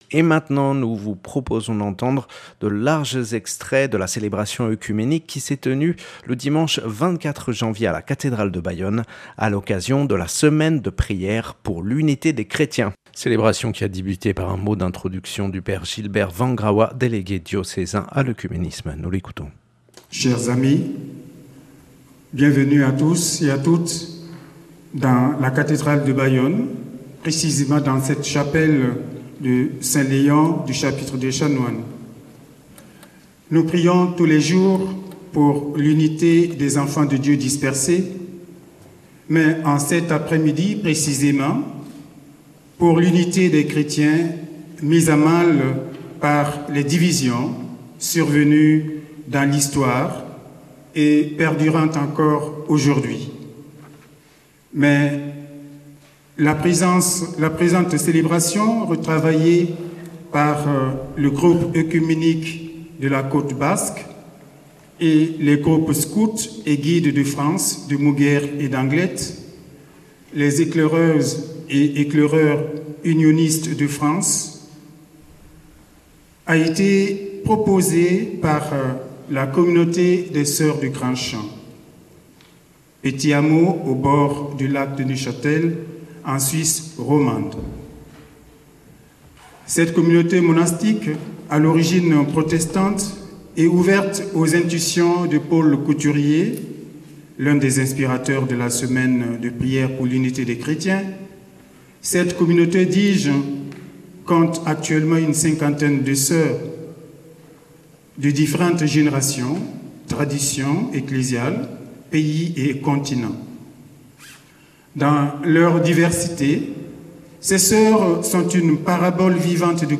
Célébration oecuménique à la cathédrale de Bayonne
Revivez la célébration enregistrée le dimanche 24 janvier 2021.